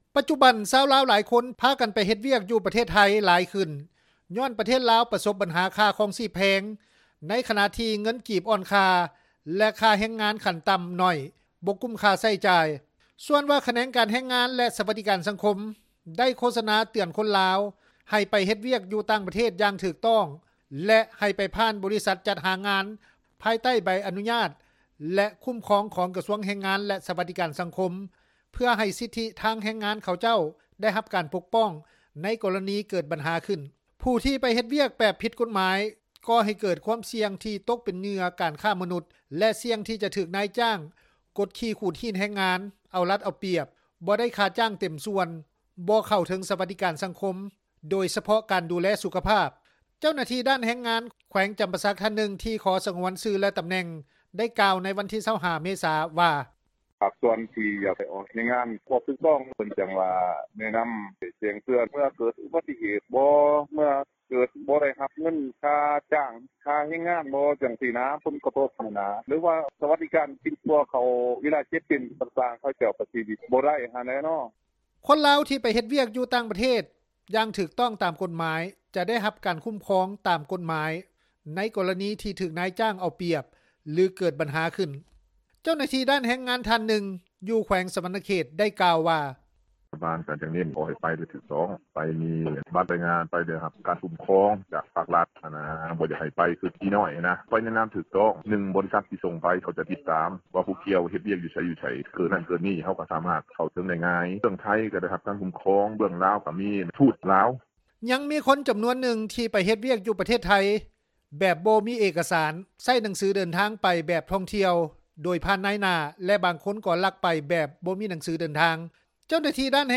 ເຈົ້າໜ້າທີ່ ດ້ານແຮງງານທ່ານນຶ່ງ ຢູ່ ແຂວງສວັນນະເຂດ ໄດ້ກ່າວວ່າ:
ຊາວລາວ ຢູ່ນະຄອນຫລວງວຽງຈັນ ໄດ້ກ່າວວ່າ:
ຊາວລາວອີກທ່ານນຶ່ງ ໄດ້ກ່າວວ່າ: